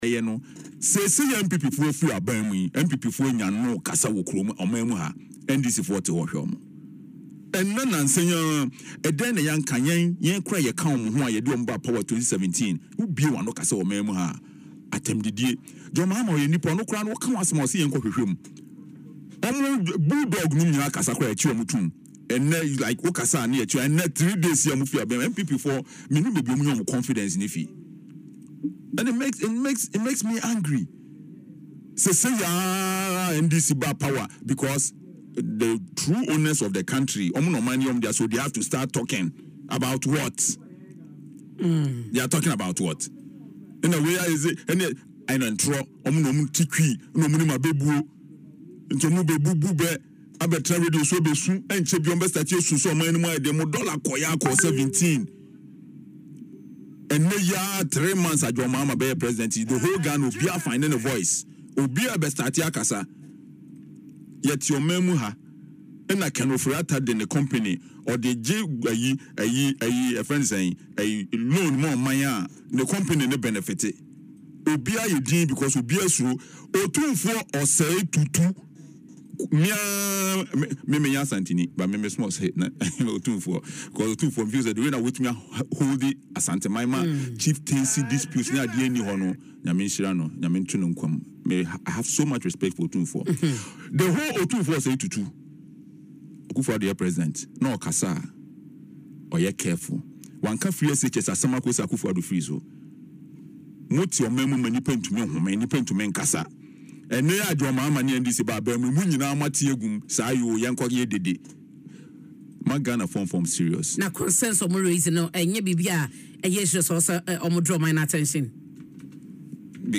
Speaking on Adom FM’s Dwaso Nsem, A Plus accused NPP members of ignoring public concerns during their tenure but now making noise on national issues after their defeat in the 2024 elections.